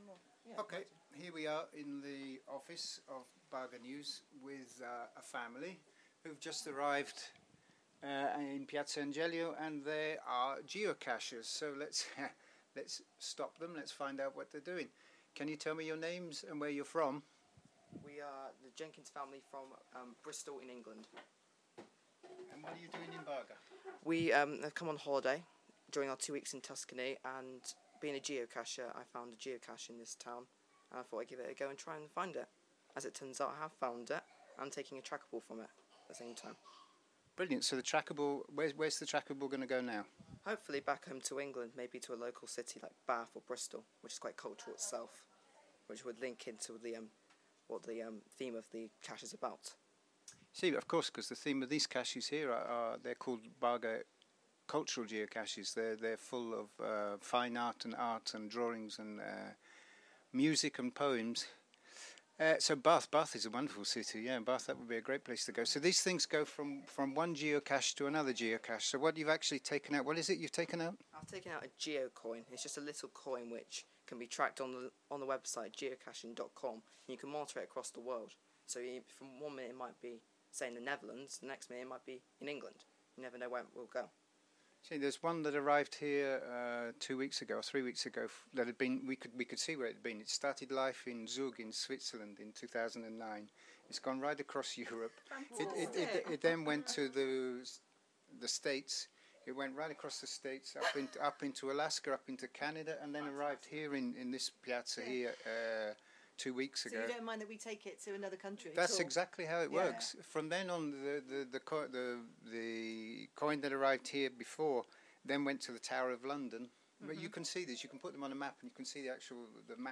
short interview